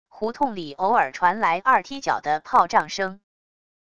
胡同里偶尔传来二踢脚的炮仗声wav音频